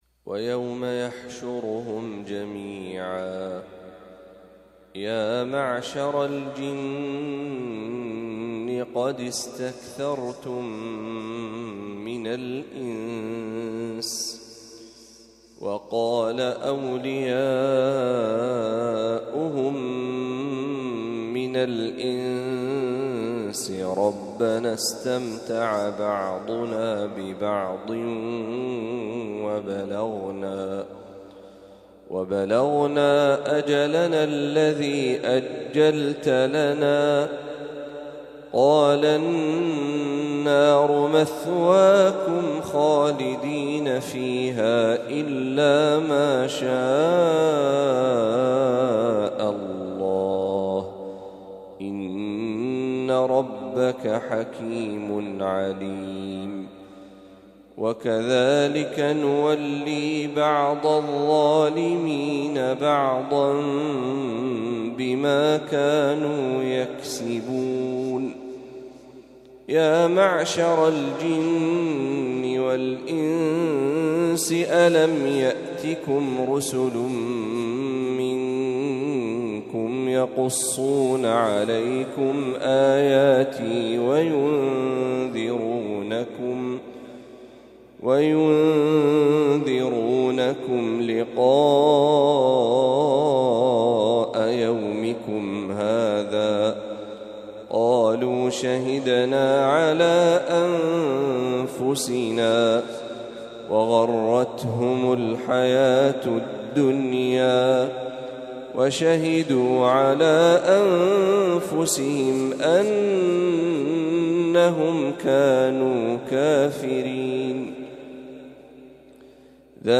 ما تيسر من سورة الأنعام | فجر الخميس ٢٣ ربيع الأول ١٤٤٦هـ > 1446هـ > تلاوات الشيخ محمد برهجي > المزيد - تلاوات الحرمين